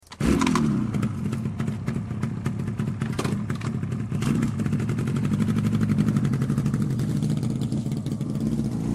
motorcycle.mp3